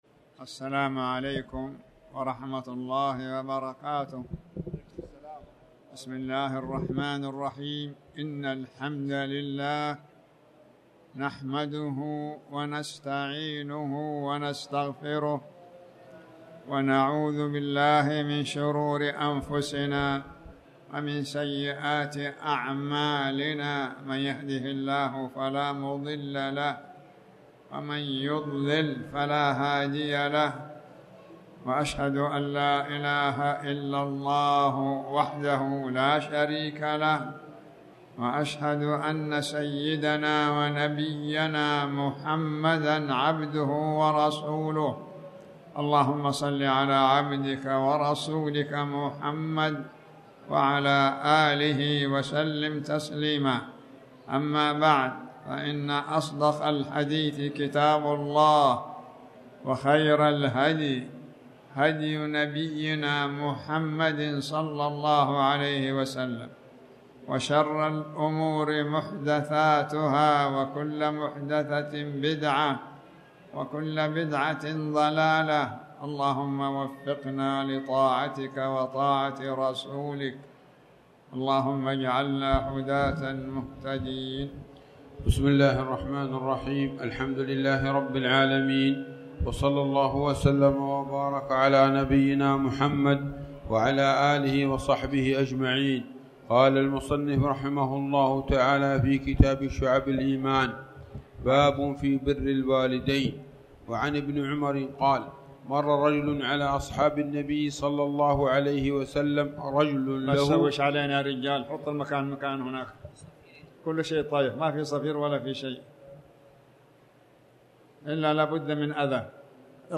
تاريخ النشر ٢ ذو القعدة ١٤٣٩ هـ المكان: المسجد الحرام الشيخ